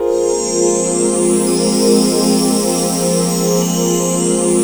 FRED PAD 2-L.wav